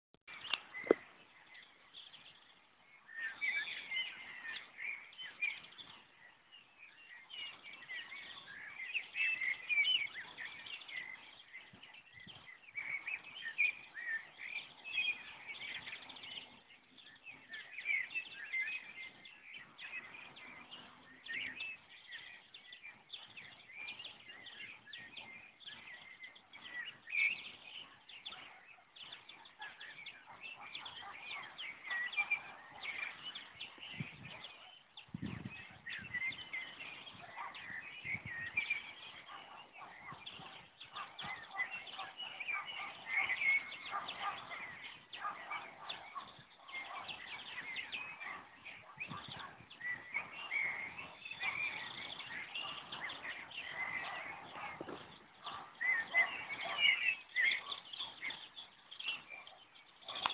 Ein wahres Konzert veranstalteten die Grillen, Vögel und Hunde. Zirpen, Zwitschern, Bellen.
Ich habe dafür die Diktiergerät-Funktion meines Handys verwendet, daher ist die Qualität natürlich nicht überwältigend, aber immerhin zufriedenstellend.
PS: Ich spiele mit dem Gedanken mir als Projekt vorzunehmen in den kommenden verbleibenden Wochen (und Monaten) an verschiedenen Orten nun jeweils eine Minute lang einen Teil des dort Hörbaren einzufangen.